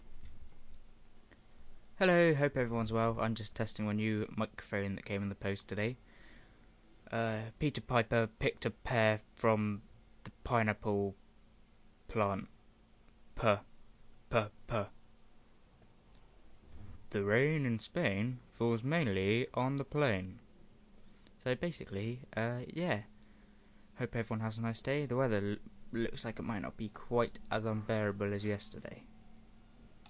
New mic test